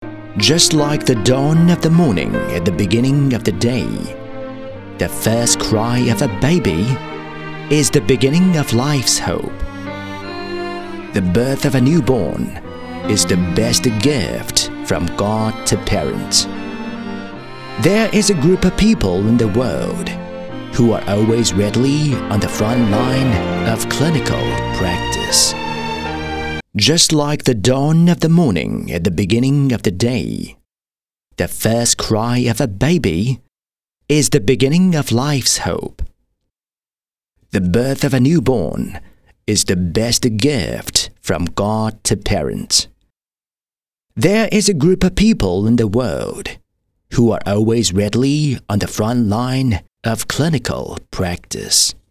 【专题】英式 专题 年轻音色
【专题】英式 专题 年轻音色.mp3